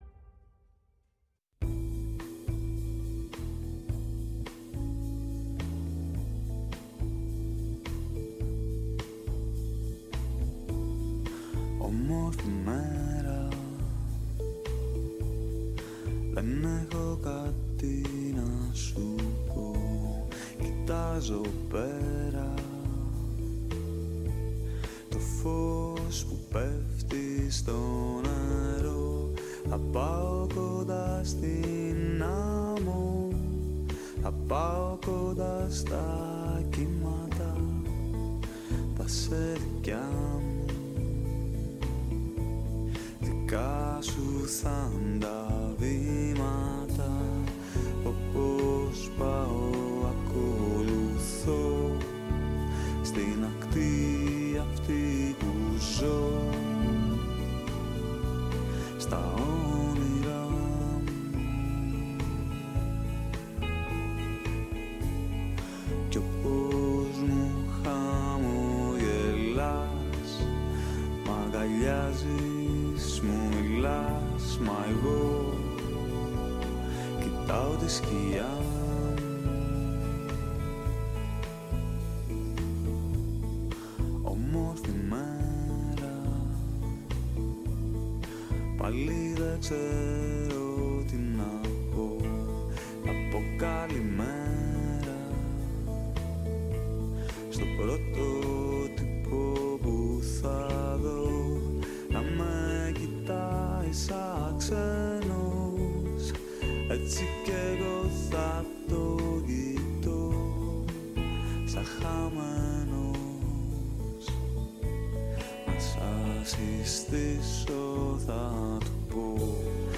Η ΦΩΝΗ ΤΗΣ ΕΛΛΑΔΑΣ Κουβεντες Μακρινες ΟΜΟΓΕΝΕΙΑ ΣΥΝΕΝΤΕΥΞΕΙΣ Συνεντεύξεις